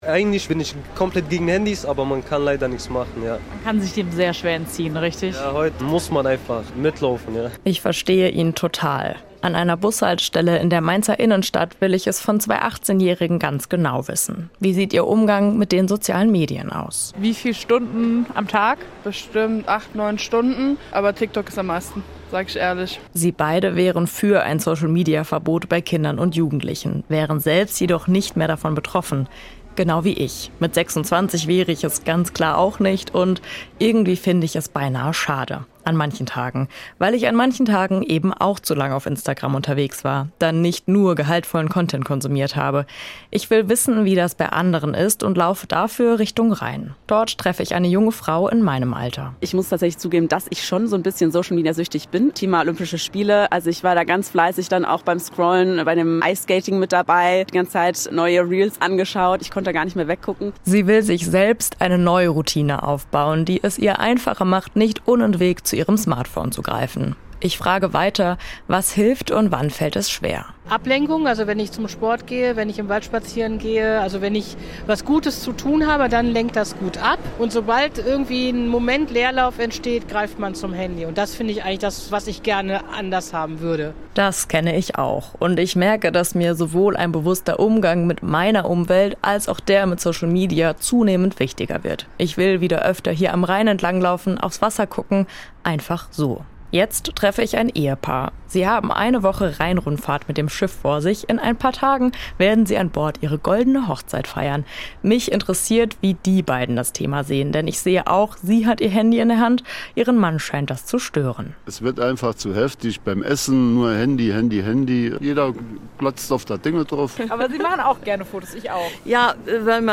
Mainzerinnen und Mainzer haben im Gespräch mit SWR1 Sonntagmorgen erzählt, was sie machen, um nicht nonstop zum Handy zu greifen. So installieren sie Apps, die andere ausgewählte Apps sperren, setzen sich ein Zeitlimit oder löschen die besonders beliebten Apps auch einfach mal ganz.